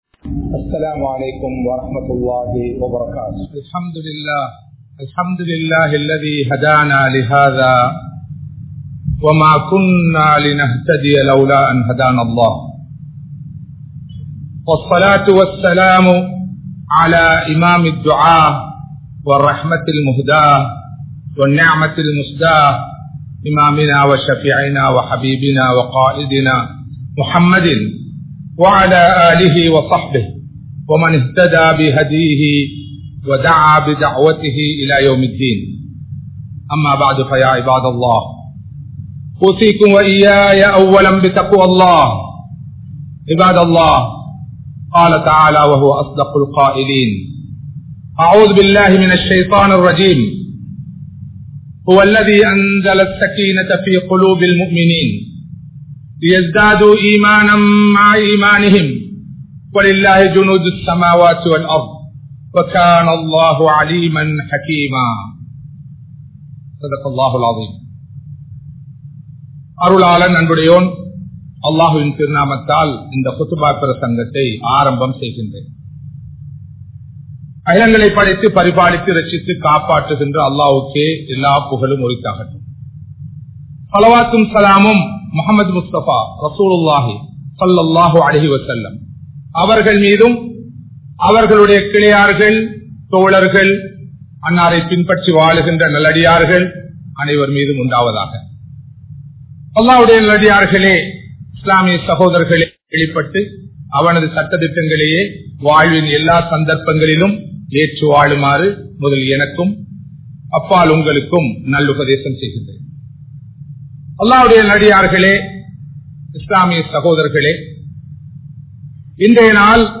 Vaalkaiel Santhoasam Veanduma? (வாழ்க்கையில் சந்தோஷம் வேண்டுமா?) | Audio Bayans | All Ceylon Muslim Youth Community | Addalaichenai
Kollupitty Jumua Masjith